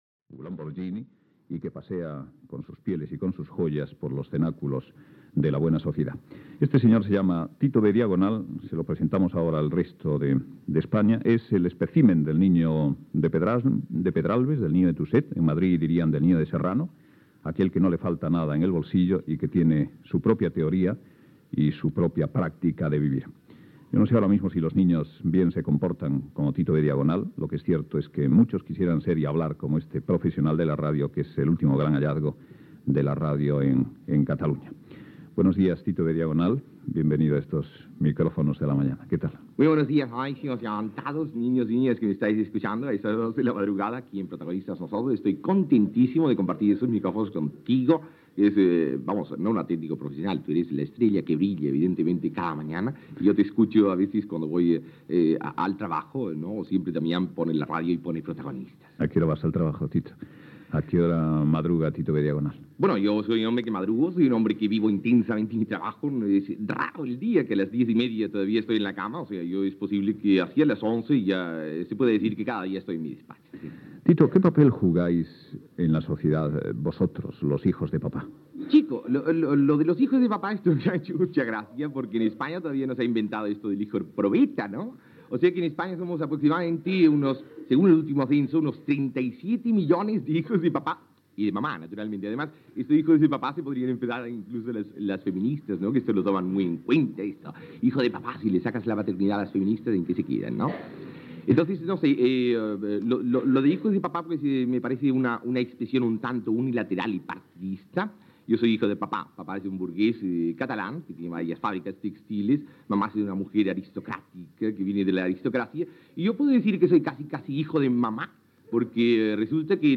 Primera entrevista a Tito B. Diagonal (Jordi Estadella) que ha publicat un disc. Conversa sobre la classe alta, la seva funció social, el dia que va baixar al metro, l'amic Tato Ganduxer, com resoldre els problemes del trànsit, les seves idees polítiques, els idiomes que parla, etc. Gènere radiofònic Info-entreteniment